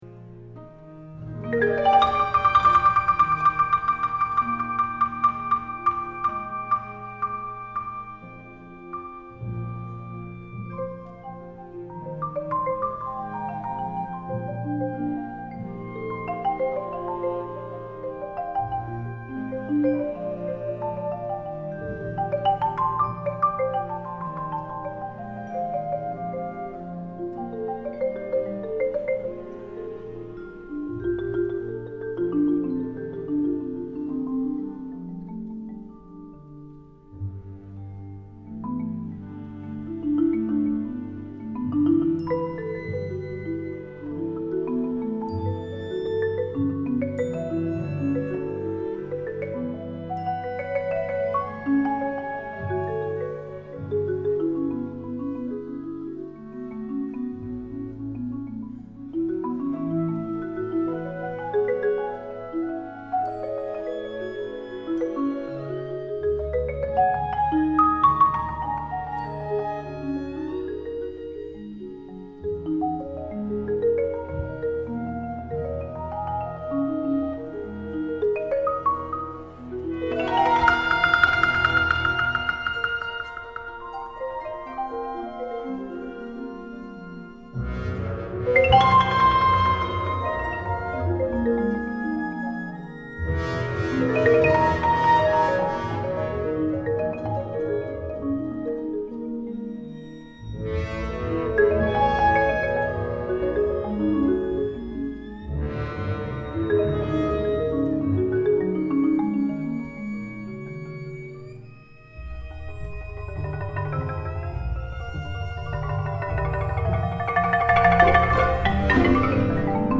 A Fantasy for marimba and Orchestra 2004